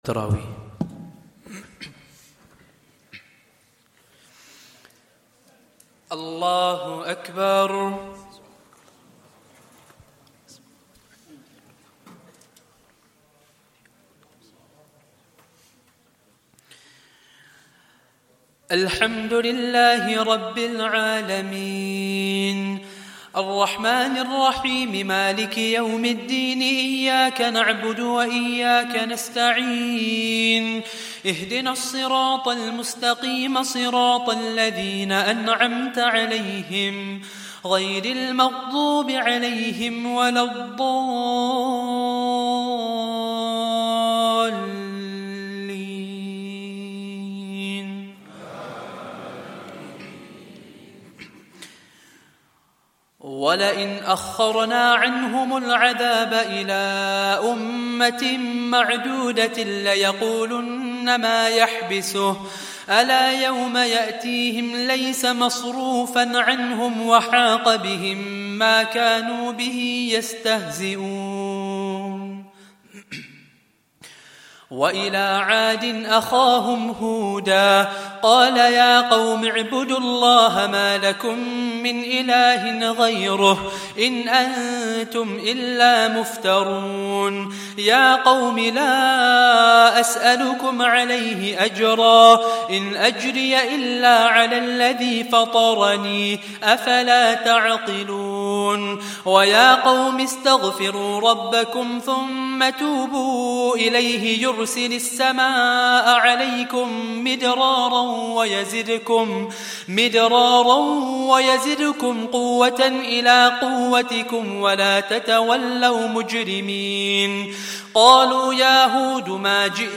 Day 10 - Taraweeh 1444 - Recital